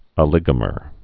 (ə-lĭgə-mər)